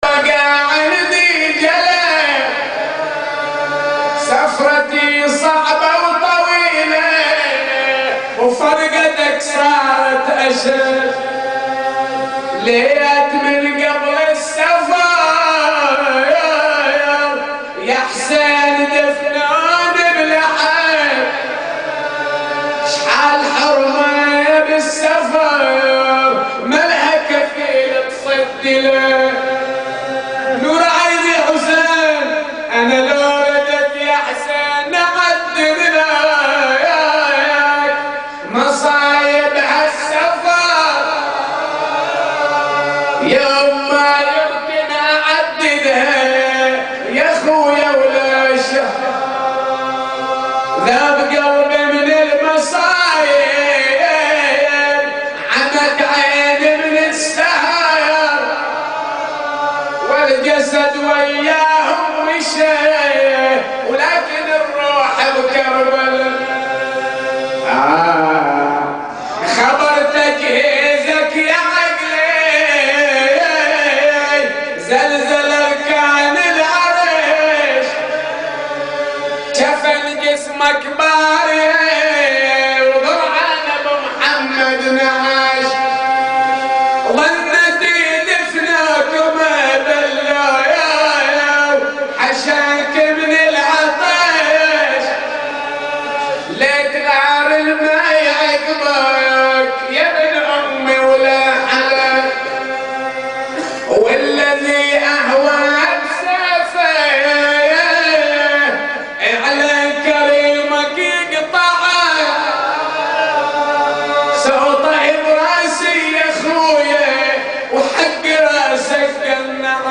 نواعي وأبيات حسينية – 4